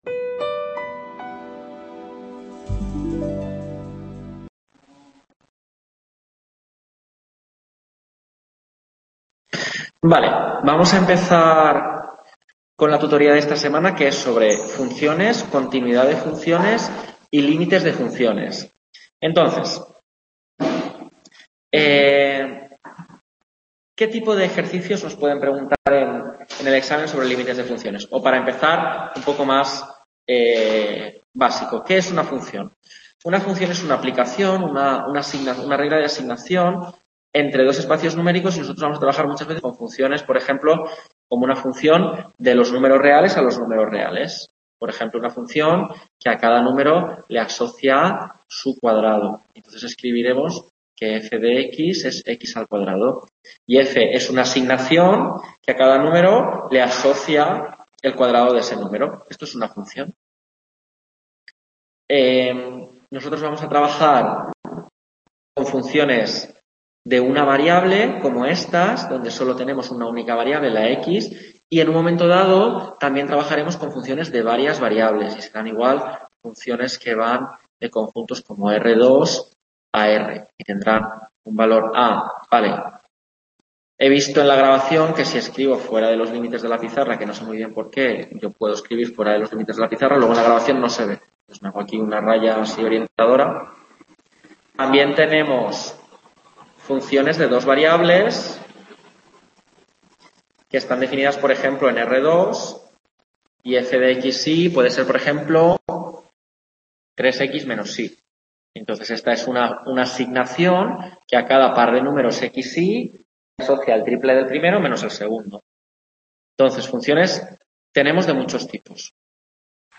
Tutoría 3 Cálculo: Continuidad de funciones y Límites de funciones